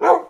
bark2.ogg